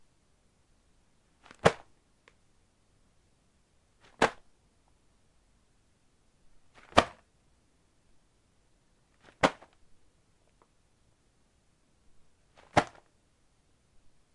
Couch » couch quick rise up 2 bip
描述：Lying down, rubbing pillow, sitting up quickly, then dropping back down
标签： couch down drop fabric lying pillow quick rise rubbing rustle sitting up
声道立体声